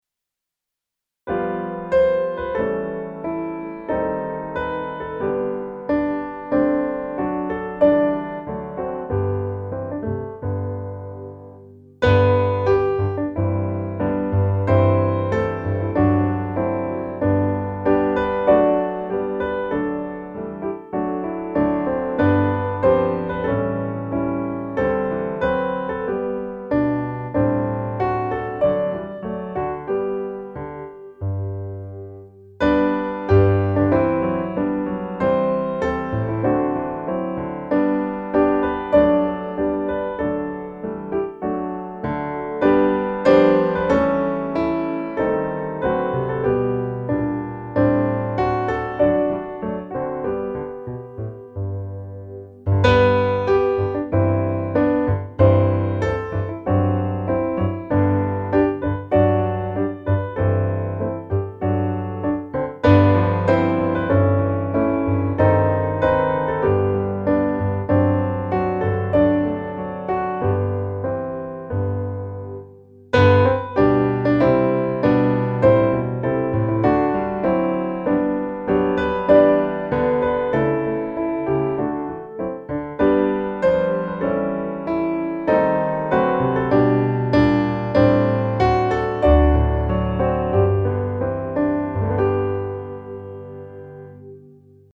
Fröjdas, vart sinne - musikbakgrund
Musikbakgrund Psalm